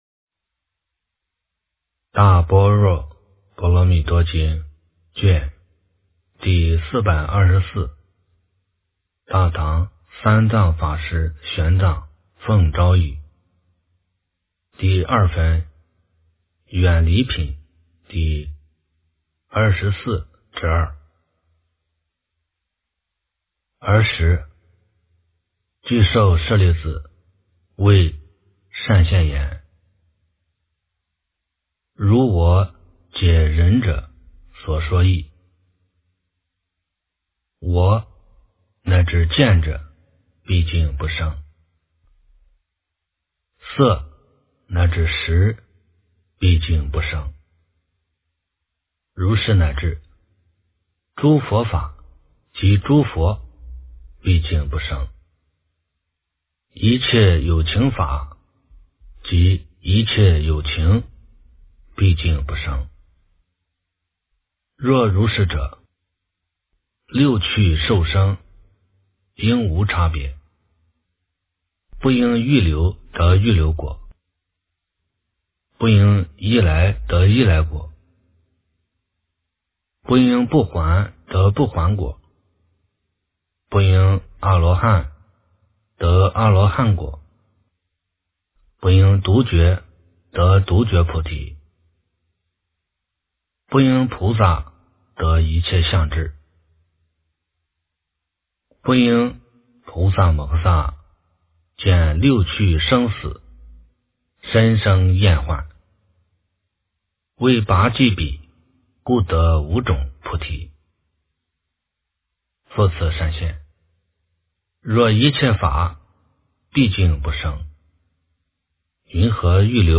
大般若波罗蜜多经第424卷 - 诵经 - 云佛论坛